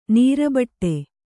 ♪ nīrabaṭṭe